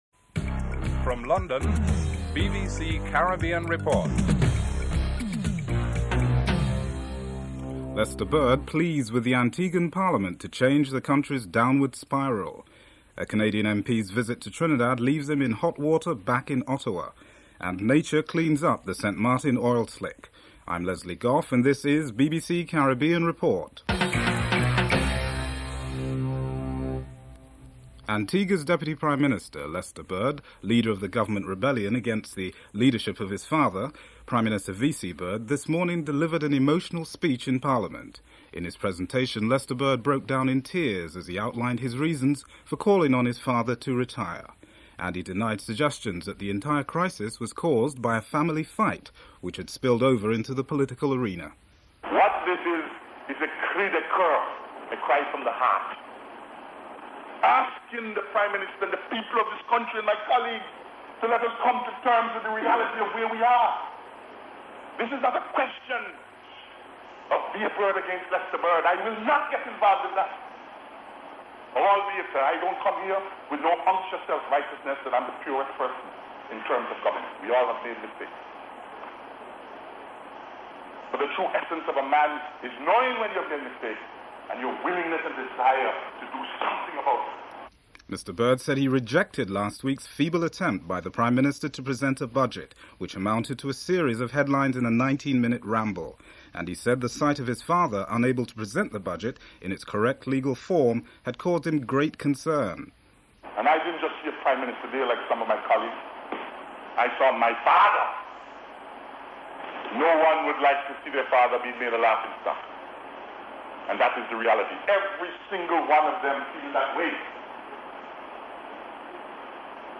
Segment 2 contains a clip of Lester Bird’s emotive speech in Parliament.